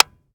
Home gmod sound weapons papa90
weap_papa90_disconnector_plr_01.ogg